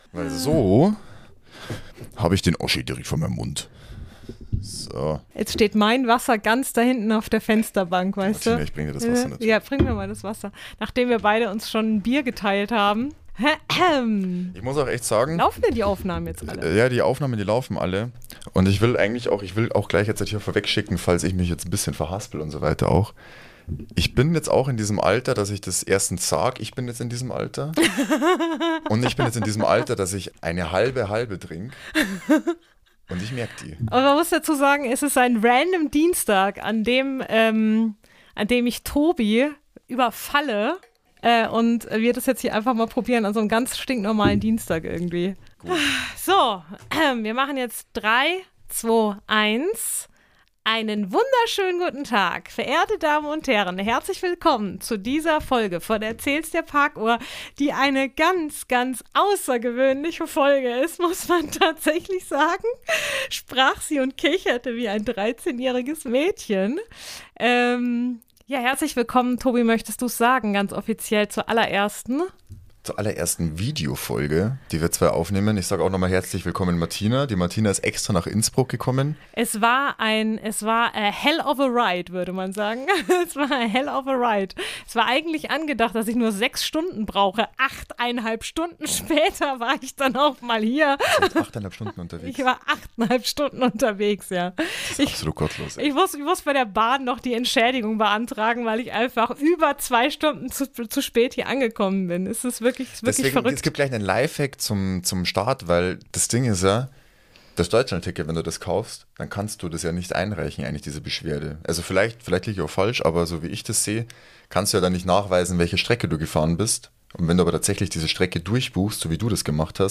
Gespräche # Laberpodcast